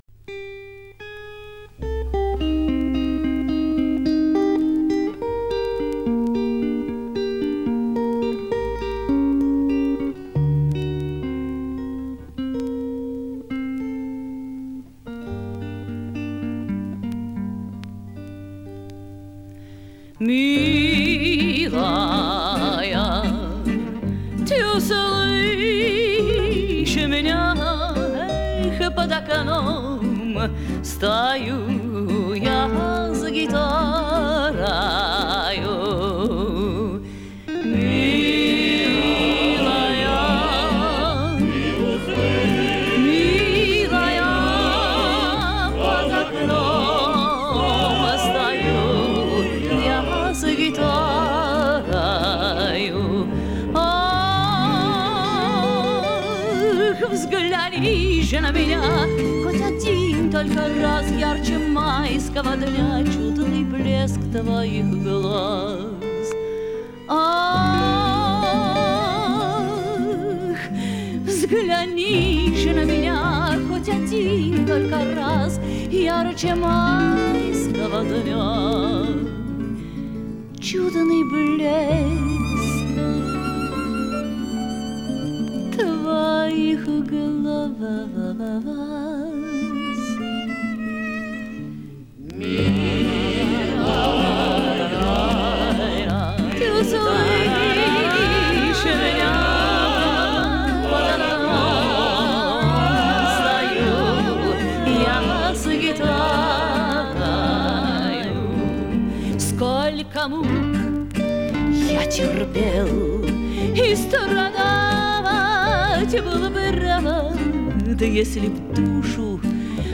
Жанр: Романс